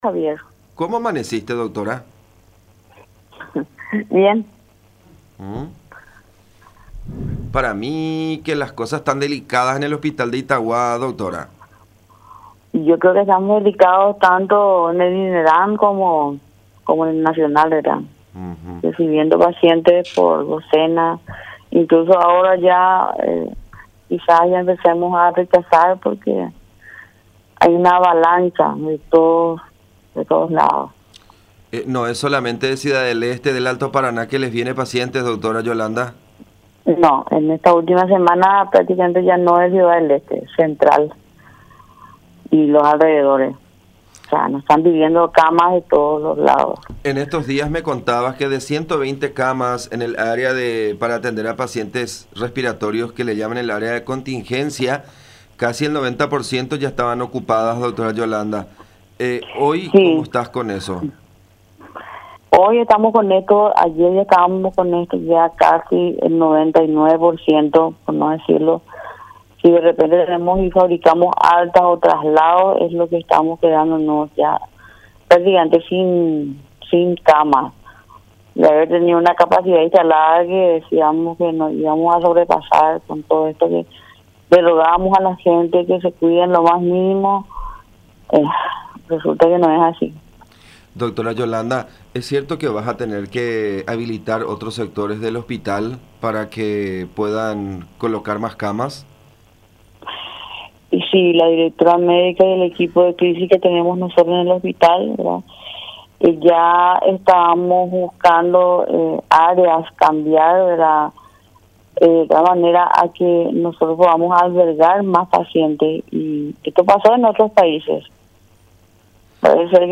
en diálogo con La Unión